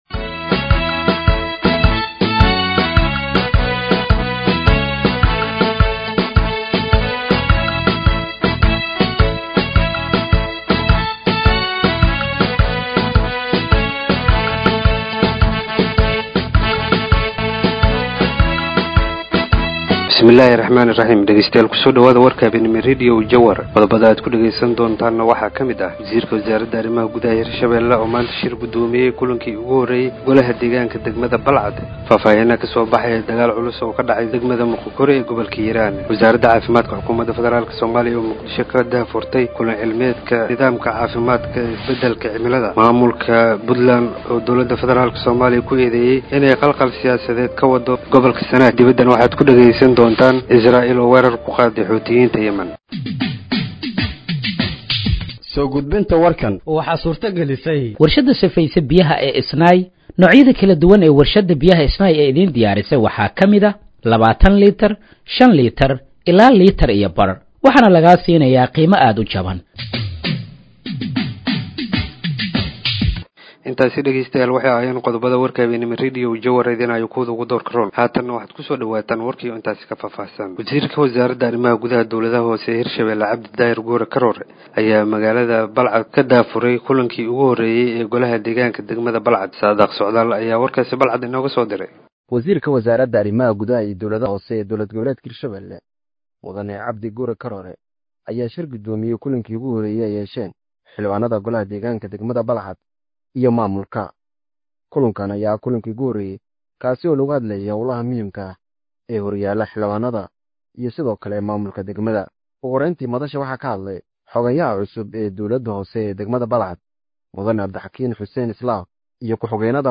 Dhageeyso Warka Habeenimo ee Radiojowhar 07/07/2025